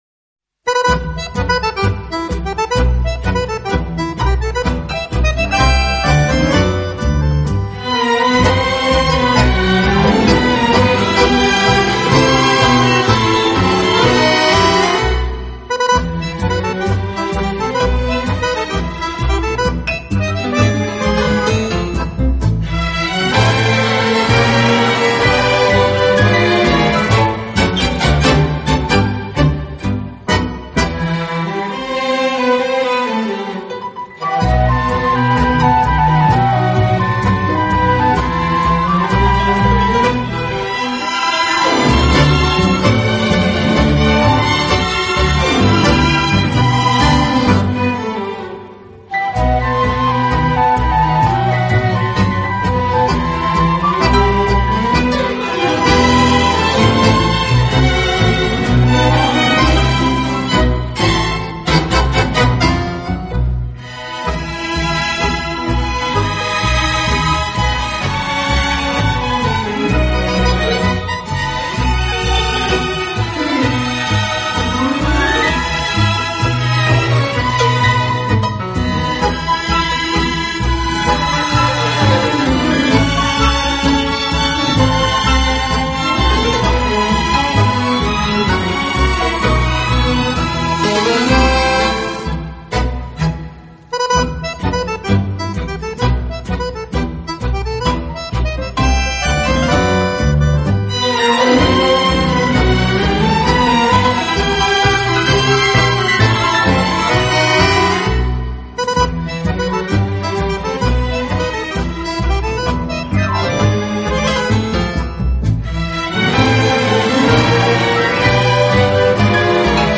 【纯音探戈】
Genre: Tango